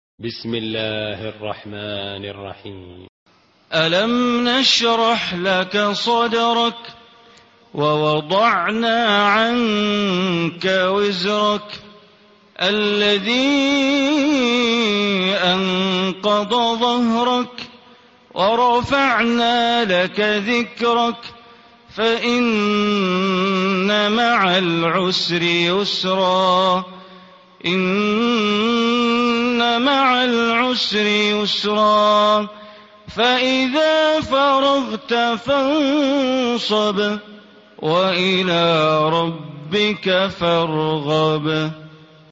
Surah Inshirah, listen online mp3 tilawat / recitation in Arabic recited by Imam e Kaaba Sheikh Bandar Baleela. Surah Inshirah is 94 chapter of Holy Quran.